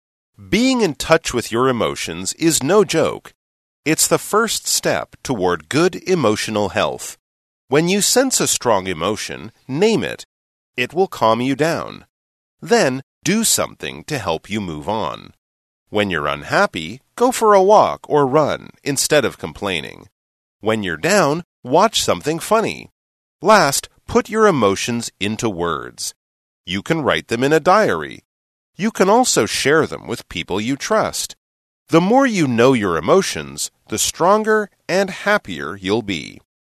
朗讀題目及練習音檔請參閱附加檔案~~